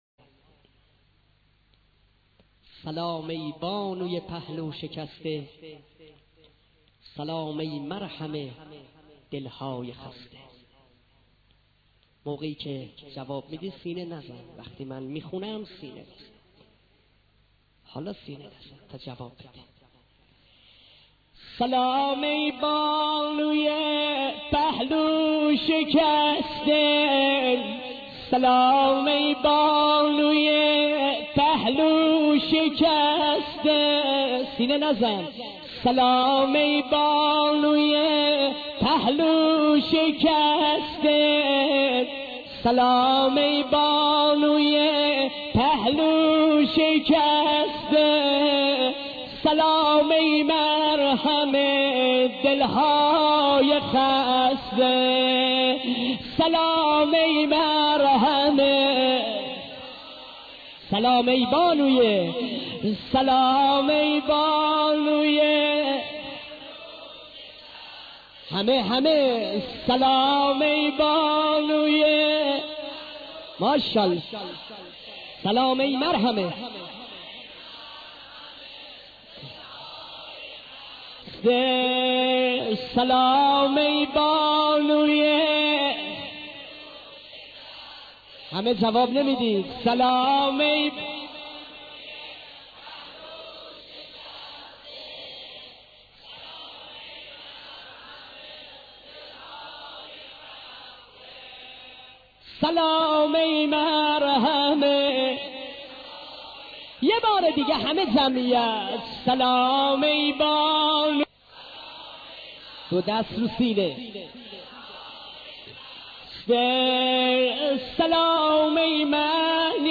نوحه شهادت حضرت فاطمه (س)